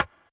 Percs
Havoc Knock 1.wav